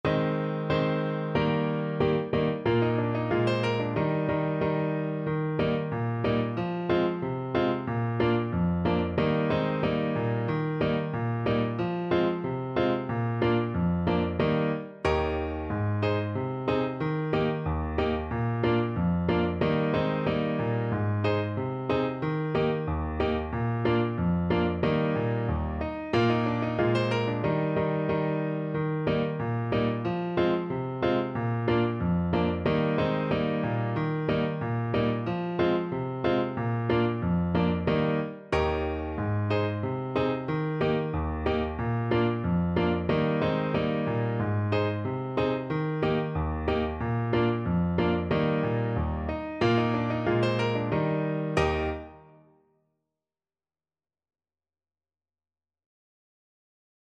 Jolly =c.92
2/2 (View more 2/2 Music)
Swiss